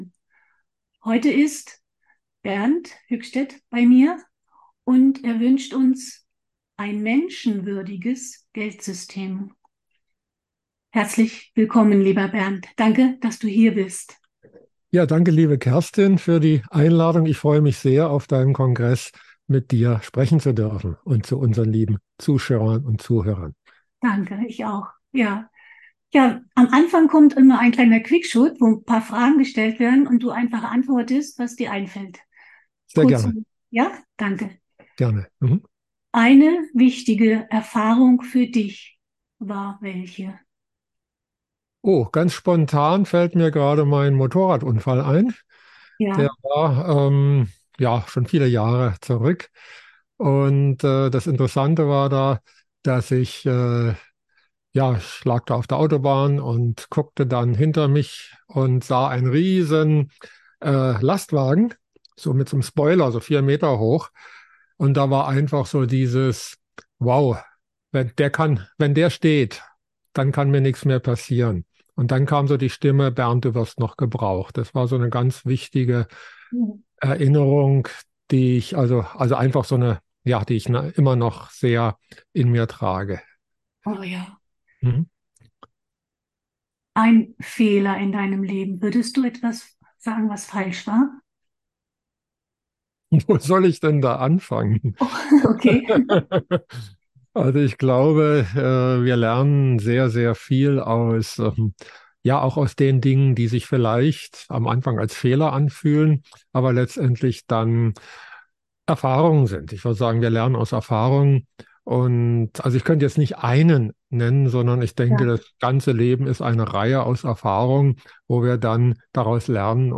Die Audios stehen in der Reihenfolge der Interviews im Kongressprogramm.